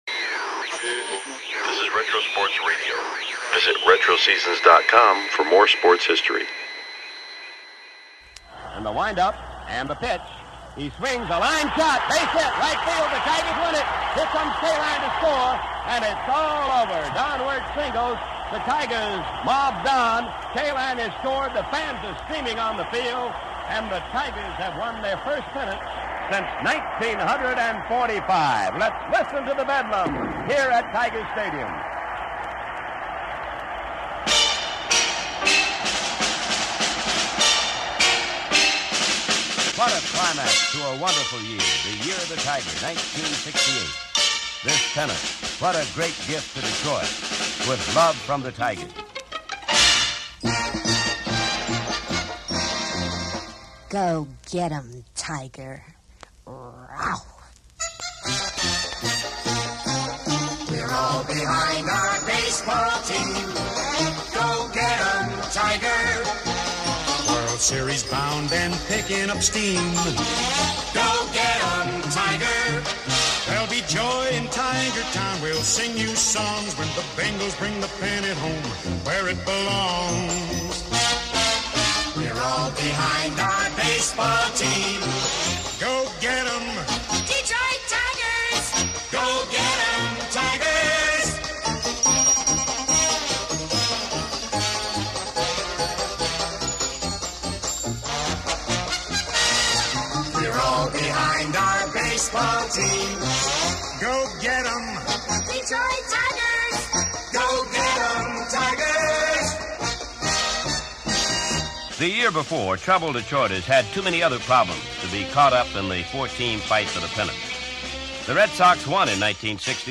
1971-Apr-12 - CHC/LAD - Chicago Cubs vs Los Angeles Dodgers - Classic Baseball Radio Broadcast – Retro Sports Radio: Classic Games from History – Podcast